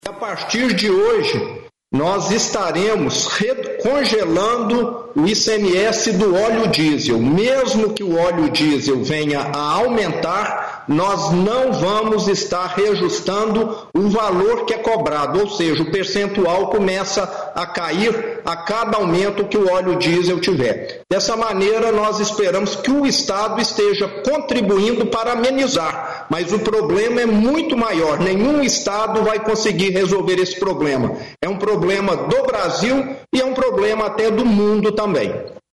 Governador Romeu Zema em entrevista à CNN BRASIL